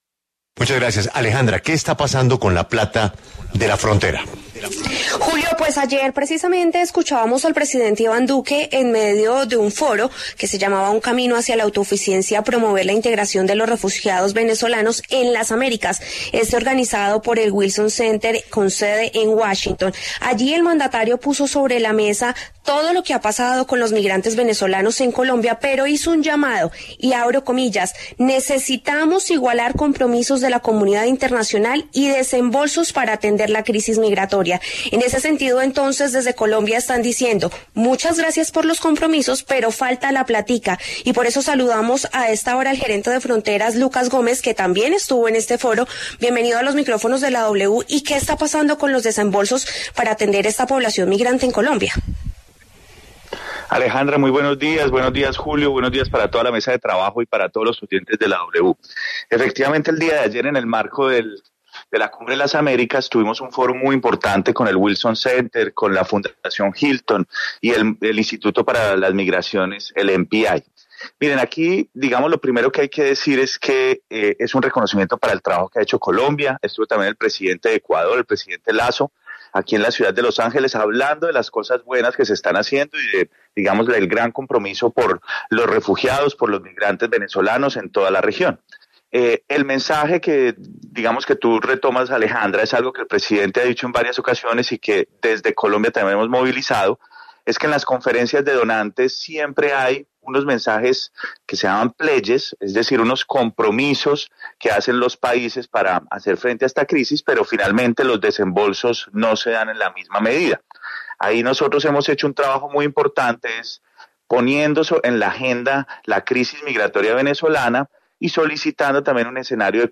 Luego que el presidente Iván Duque alzara la mano en repetidas ocasiones para recordar los acuerdos y compromisos de la comunidad internacional para solventar la crisis y situación económica generada por la migración de ciudadanos venezolanos, el gerente para las Fronteras, Lucas Gómez, habló en los micrófonos de La W y si bien reconoció las demoras, también aseguró que el Gobierno ha implementado nuevas alternativas.